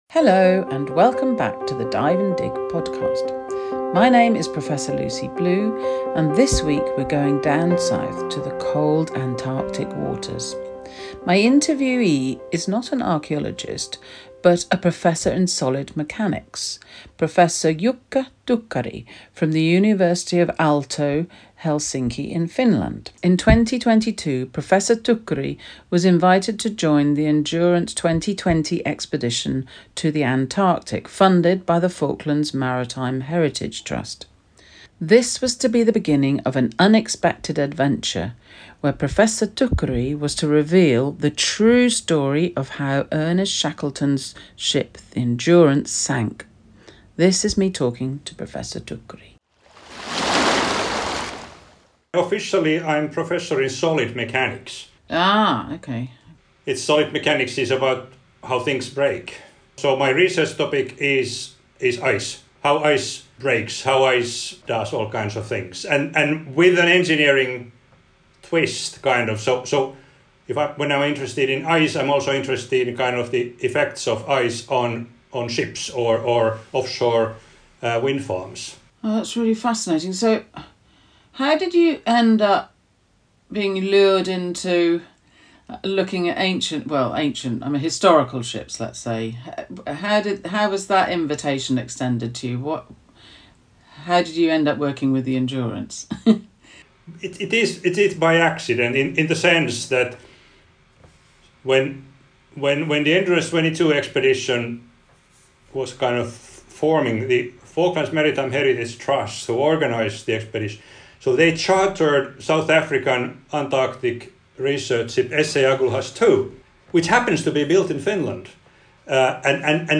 The discussion explores how the ship’s structure, the ice conditions in the Weddell Sea, and the loss of the rudder combined to seal its fate on 21 November 1915.